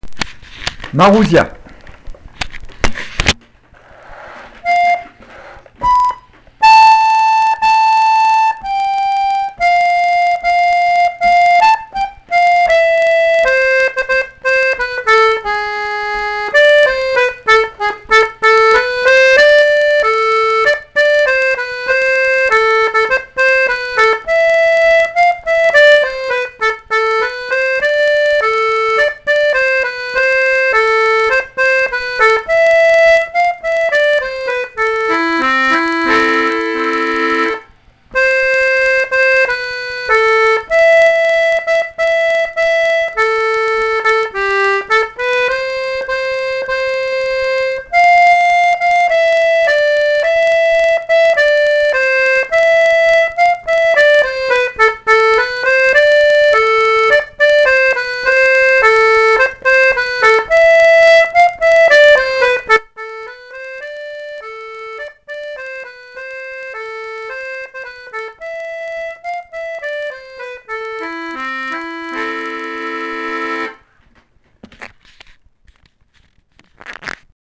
l'atelier d'accordéon diatonique
Chamamé